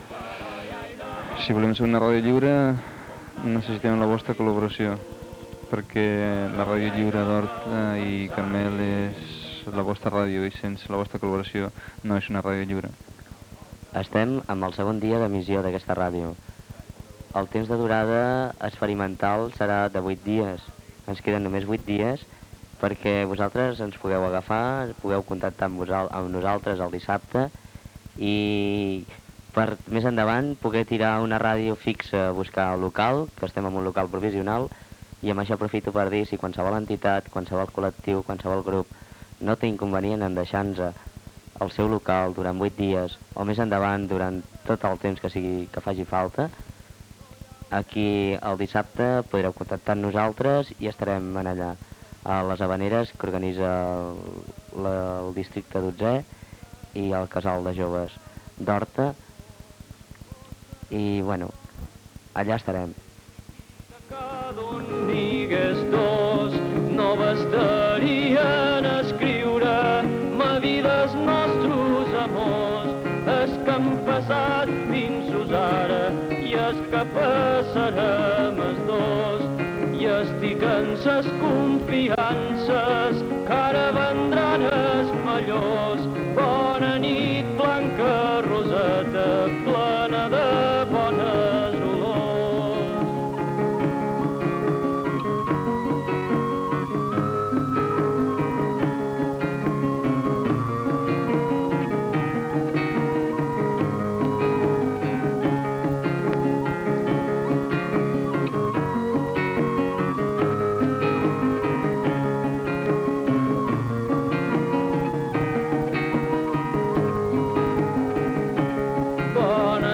999bcd06f6fe0b482d1f094dd11cb5bda9085d0d.mp3 Títol Ràdio Farigola Emissora Ràdio Farigola Titularitat Tercer sector Tercer sector Lliure Descripció Segon dia d'emissió. Demanda de local, cançó i identificació.